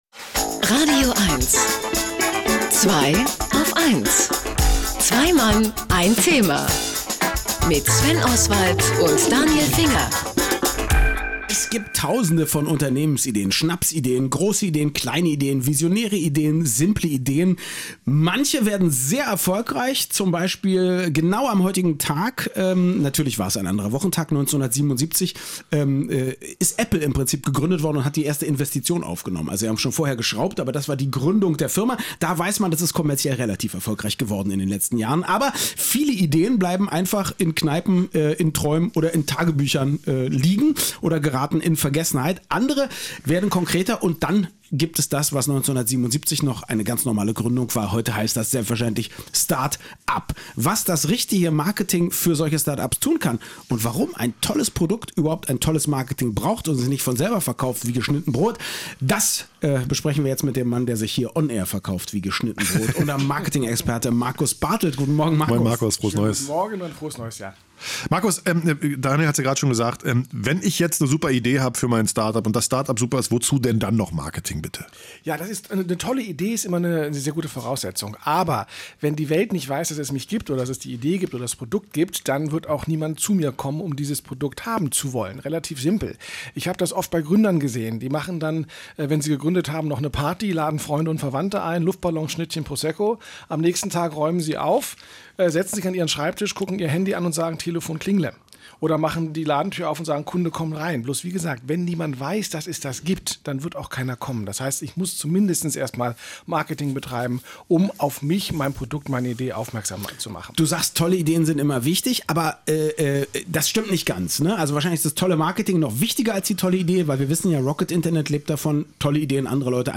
Meiner lautet ja, im kommenden Jahr mindestens genauso oft zu Gast bei „Zweiaufeins“ im „radioeins„-Studio zu sein wie im letzten.